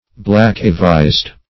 Black-a-vised \Black"-a-vised`\, a. Dark-visaged; swart.